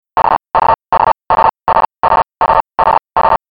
Přenosová rychlost vybavení použitého v Bernhardu musela být vyšší než u „běžného polního přístroje, podle tohoto zdroje je odhadována na 350 bps, akustická frekvence signálu byla s největší pravděpodobností shodná s „polním“ Hellschreiberem – 900Hz.
Jak asi přibližně mohl znít signál radiomajáku ve sluchátkách, si můžete poslechnout i dnes. Jedná se samozřejmě o simulaci, nikoliv o historický audiozáznam - takový nejspíš nikde neexistuje.